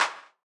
IBI Clap.wav